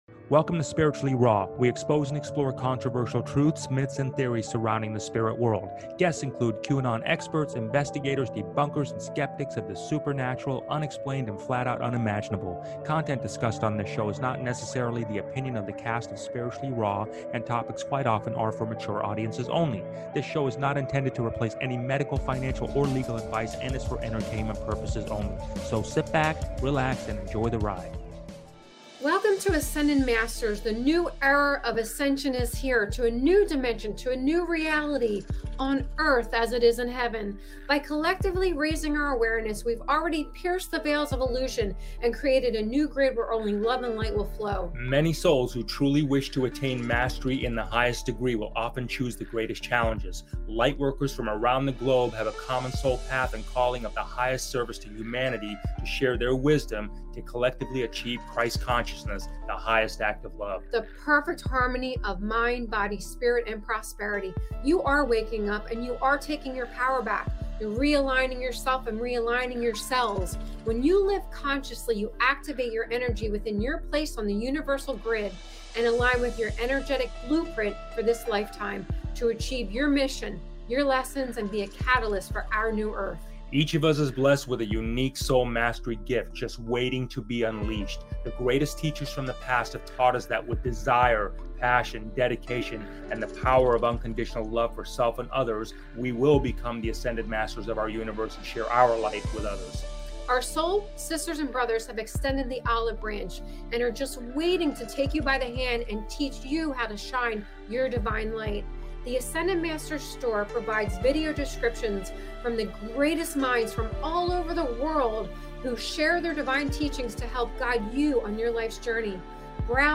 Talk Show Episode
We're bold and brutally honest with a savvy sense of humor.